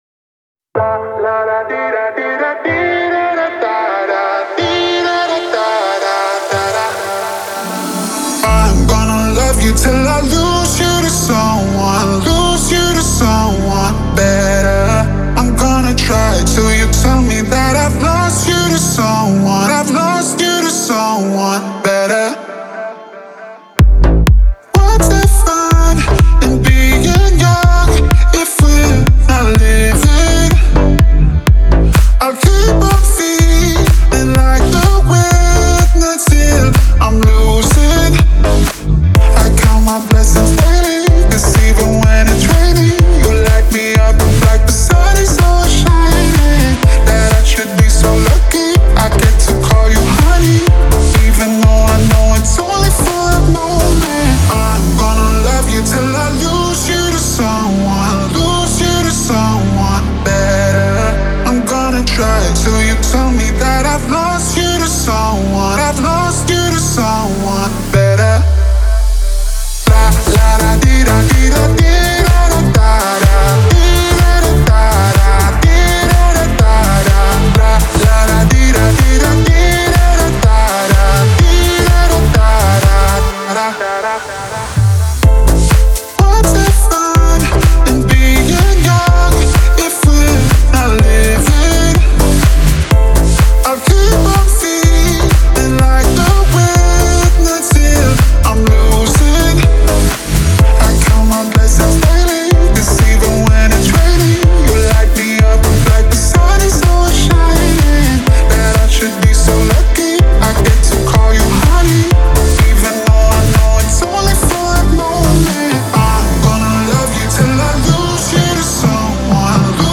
это трек в жанре электронная танцевальная музыка